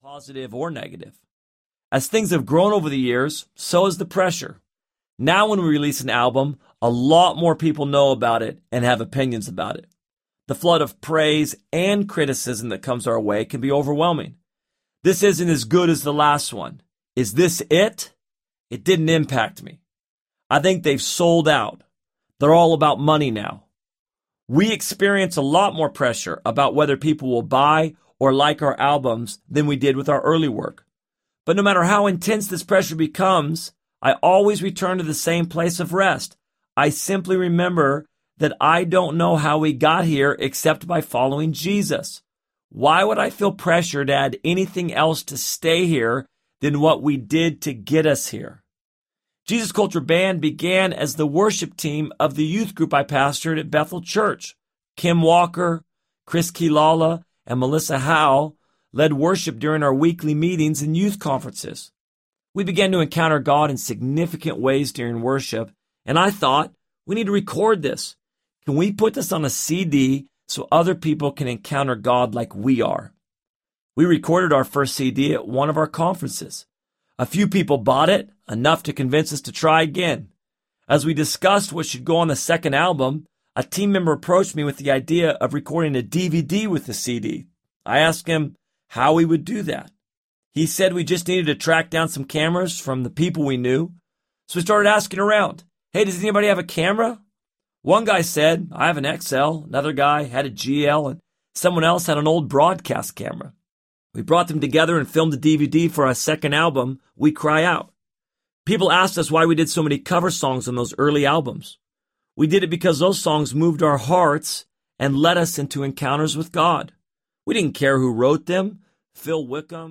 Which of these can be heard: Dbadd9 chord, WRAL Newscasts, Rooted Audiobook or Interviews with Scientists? Rooted Audiobook